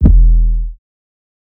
Deep Stub 808.wav